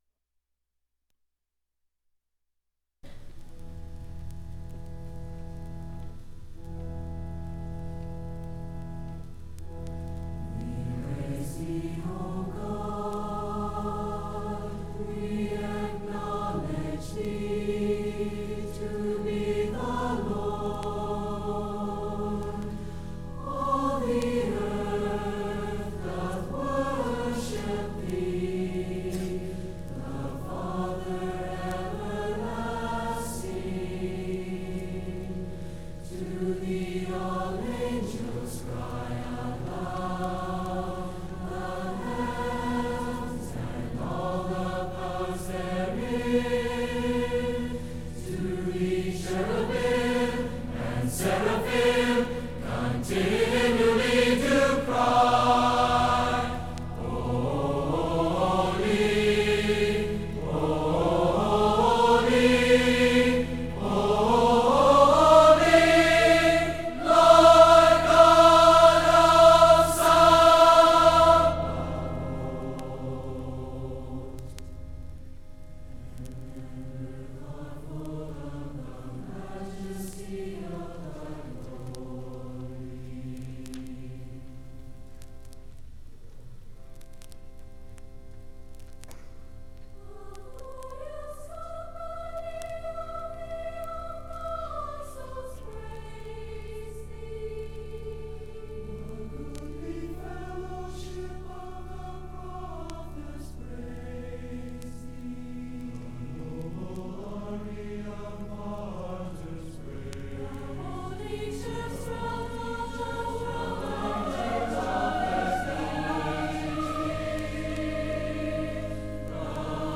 de48e669d3d2ecda82e9f8c4e774e342c528f702.mp3 Title 1978 Music in May chorus and band performance recording Description An audio recording of the 1978 Music in May chorus and band performance at Pacific University.
It brings outstanding high school music students together on the university campus for several days of lessons and events, culminating in the final concert that this recording preserves.